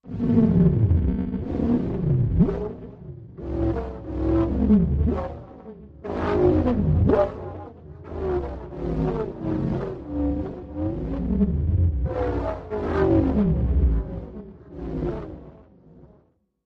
Tube Welder, Machine, Hollow Tube, Deep Random, Fluxuate, Space Message